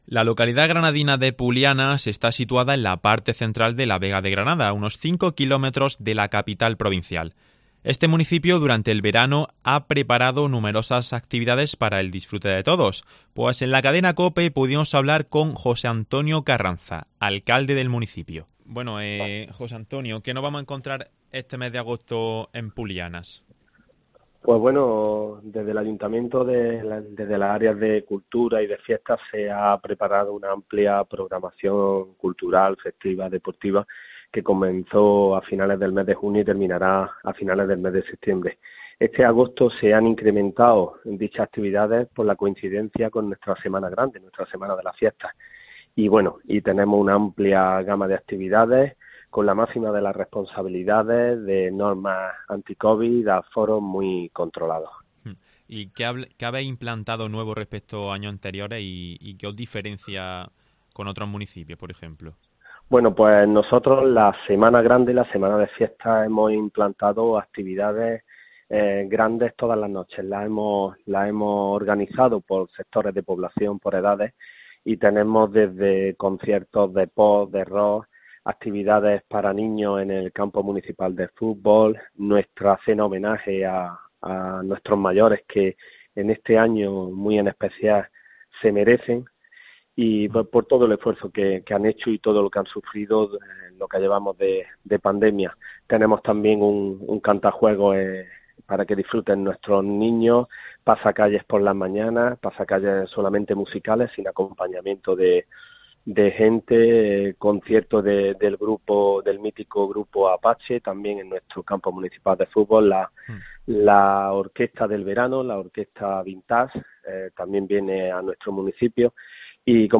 En la cadena COPE, pudimos hablar con José Antonio Carranza, alcalde del municipio.